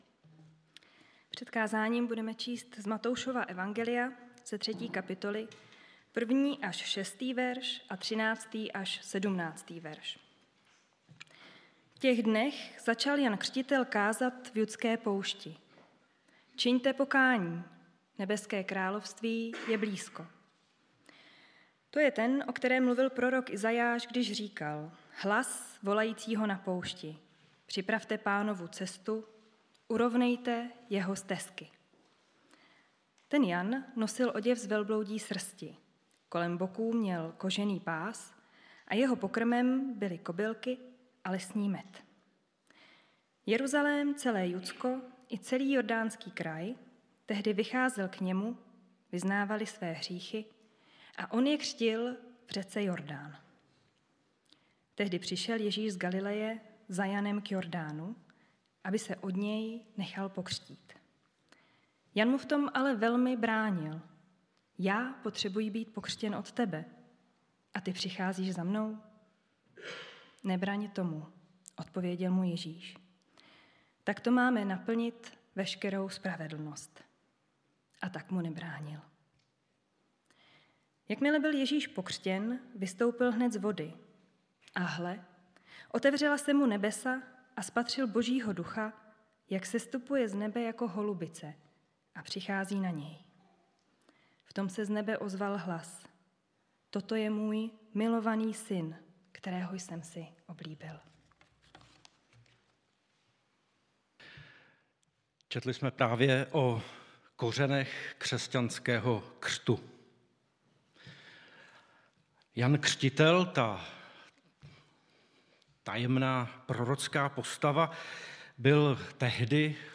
Křestní bohoslužba